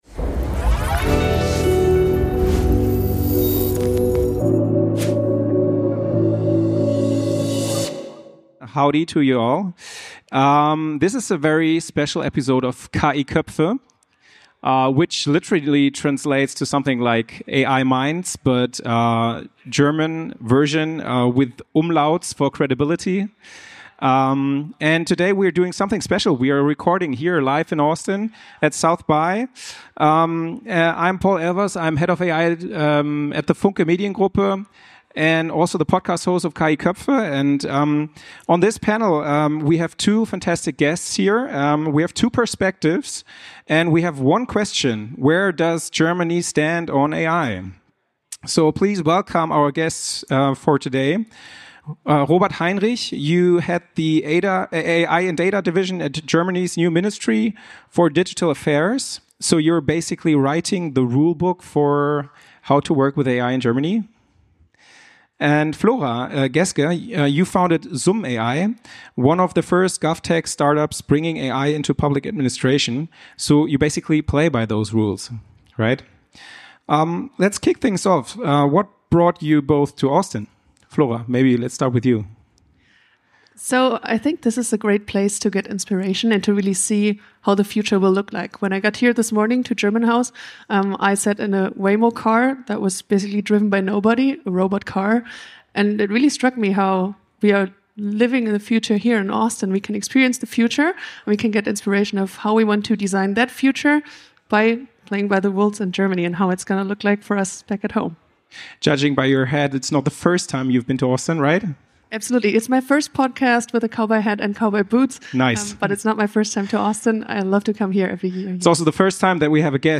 – Live aus Austin (SXSW-Special) ~ KI Köpfe Podcast